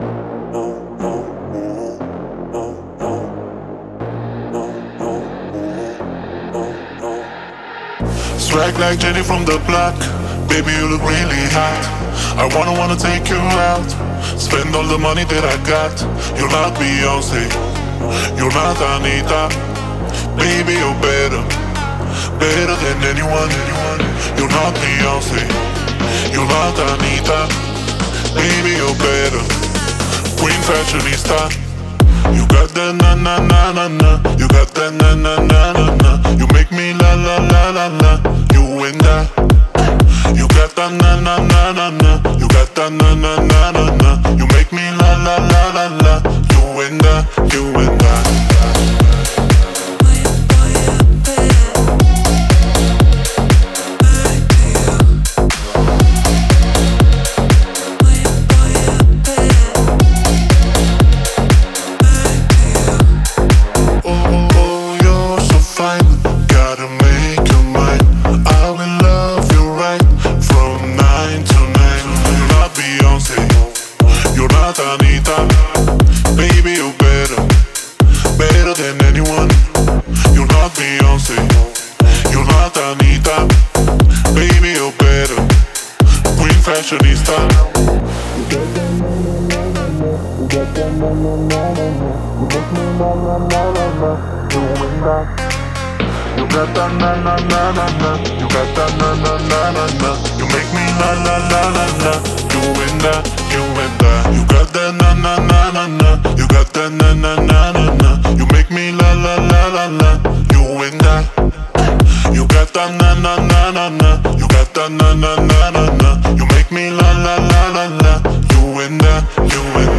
это энергичная танцевальная композиция в жанре поп и R&B